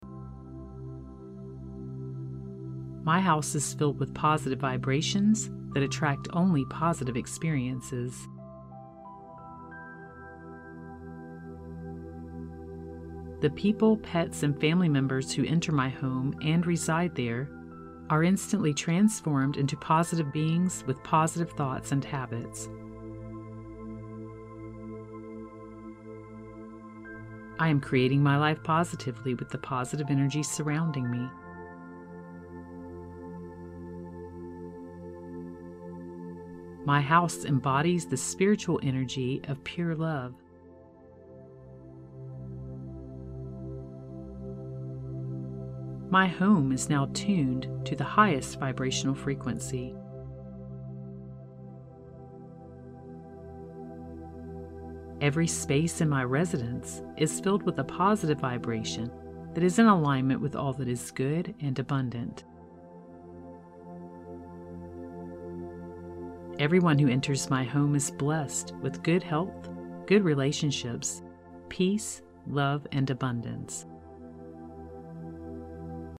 This “raise the vibration of your home” affirmation track is designed to fill the space of your home (and everyone who enters there) with positive vibrations. It has been combined with a 417hz track, also known as the “miracle tone”, to cleanse all negative energy and mental blockages, and promote easy and positive life changes.